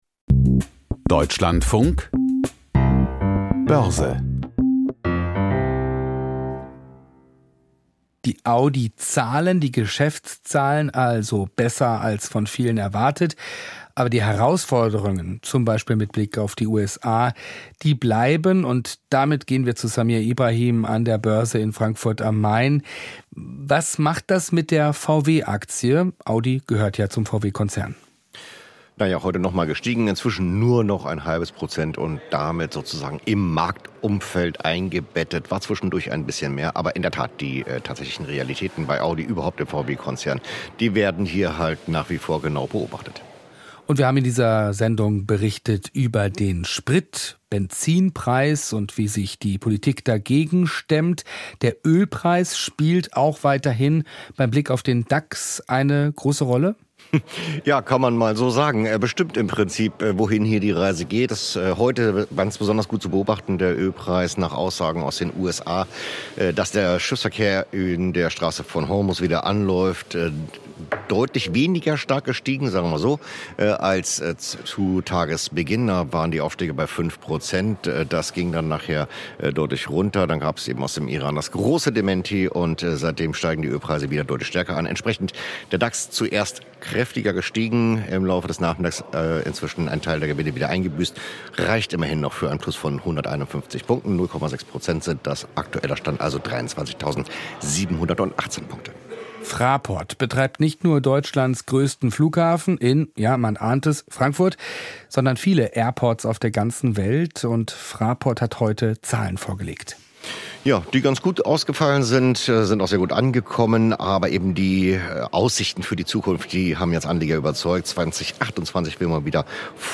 Börsenbericht aus Frankfurt a.M.